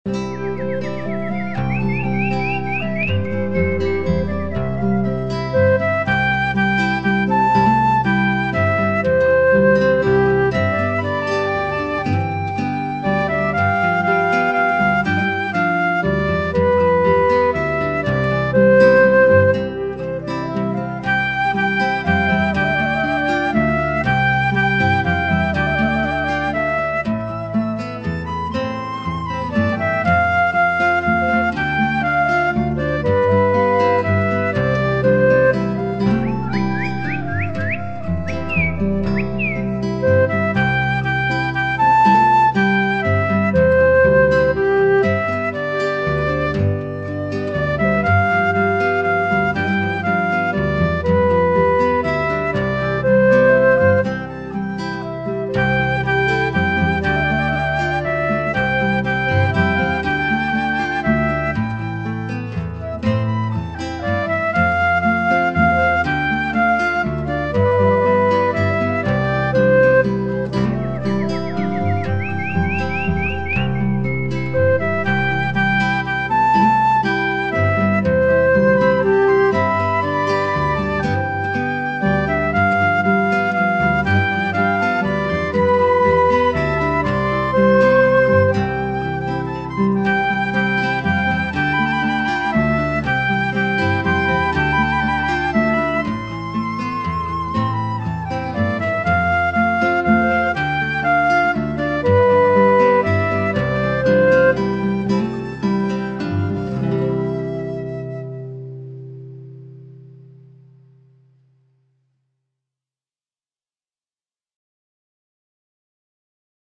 Música infantil
Canciones